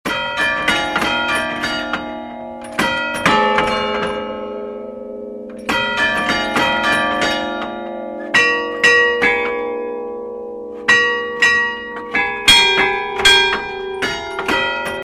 au carillon de la basilique
Un album entièrement interprété à la cloche d'église !